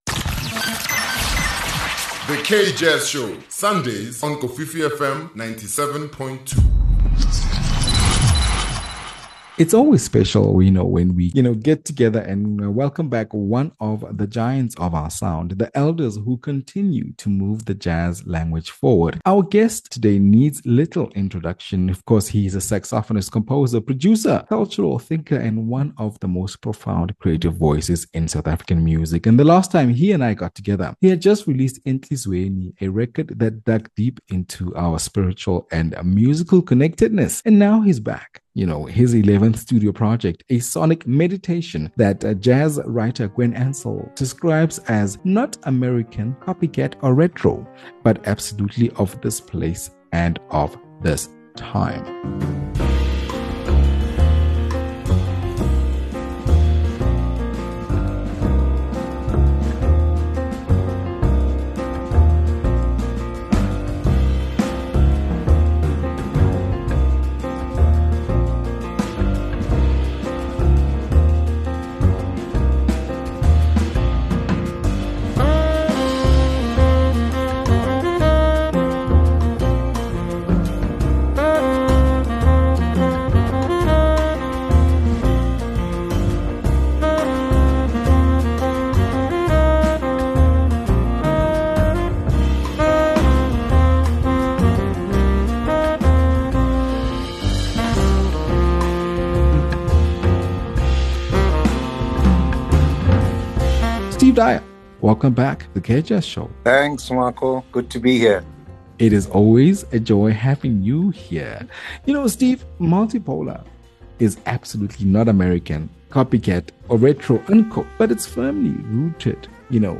It’s always special when we welcome back the giants of our sound, the elders who continue to move the language of jazz forward. Our guest needs little introduction; he’s a saxophonist, composer, producer, cultural thinker, and one of the most profound creative voices in South African music.